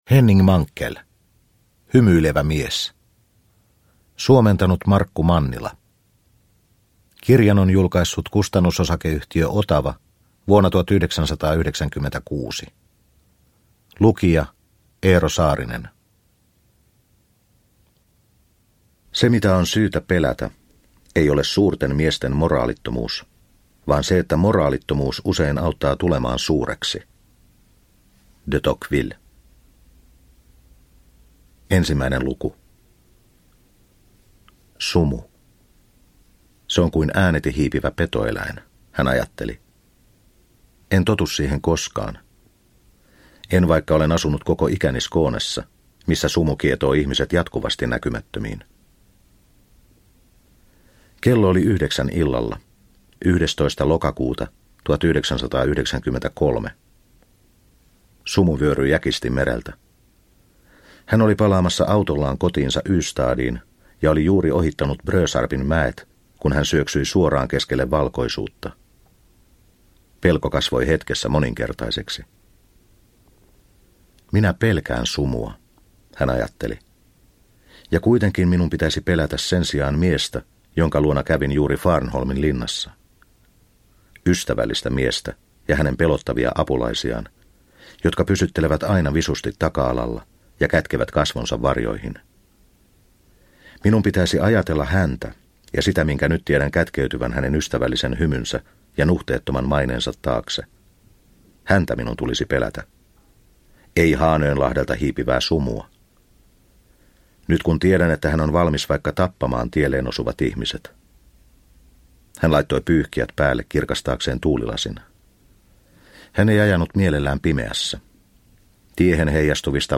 Hymyilevä mies – Ljudbok – Laddas ner